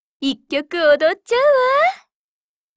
贡献 ） 分类:碧蓝航线:语音/音乐 您不可以覆盖此文件。
Cv-80201_warcry.mp3